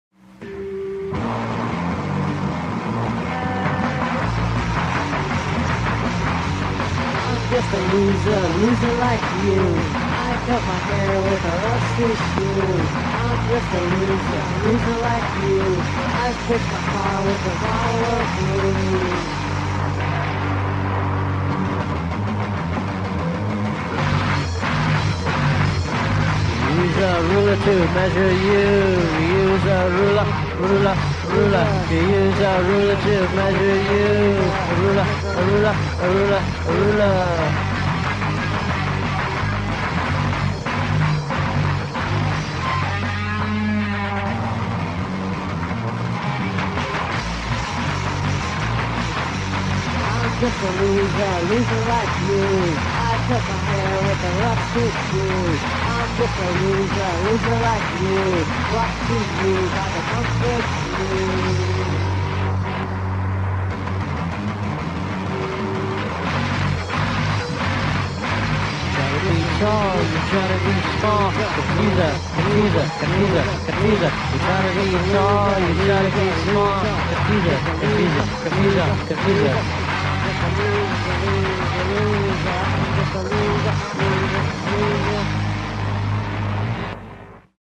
Rock and Roll after all, this has been a
Simple and poor.